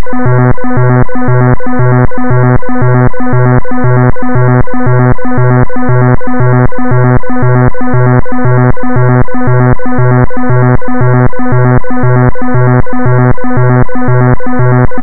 Sounds like an odd ringtone for a phone.
Sample Rate: 4000 Hz
Channels: 1 (mono)